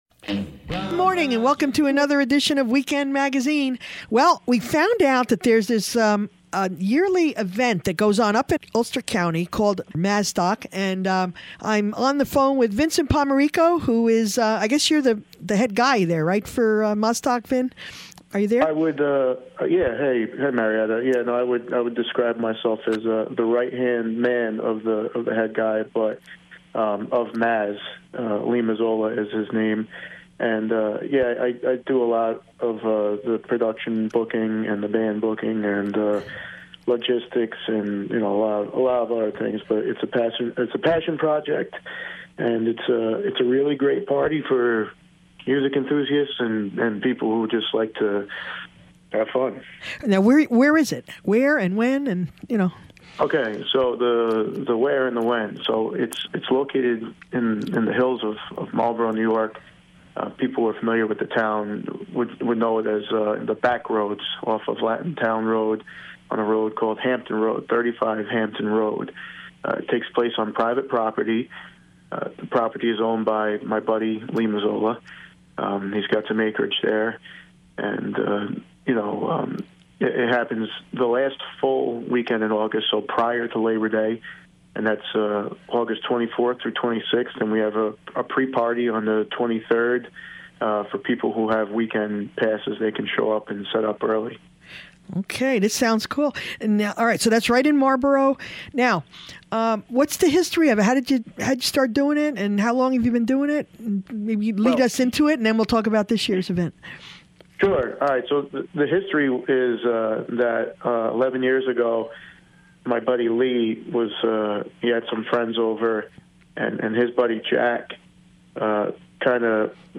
visits the Mazzstock Music Festival in Marlboro New York